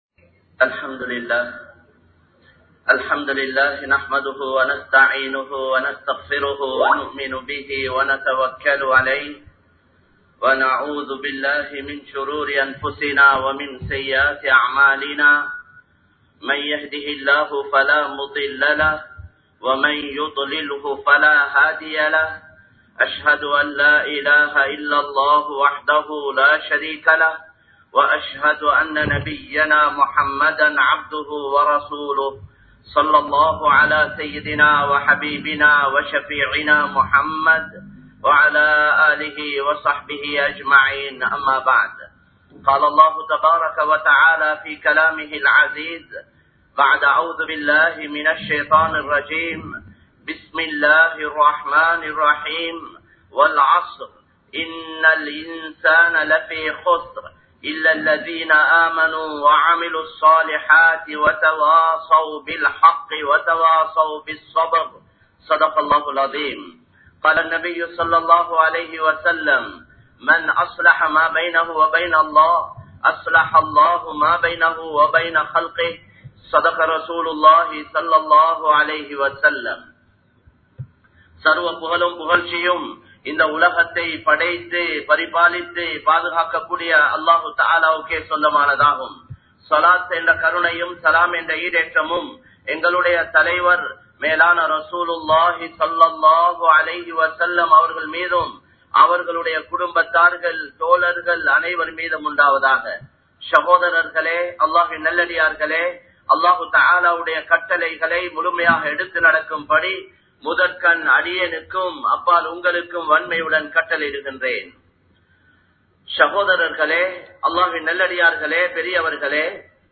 படைத்தவனை நம்புங்கள் | Audio Bayans | All Ceylon Muslim Youth Community | Addalaichenai
Colombo 03, Kollupitty Jumua Masjith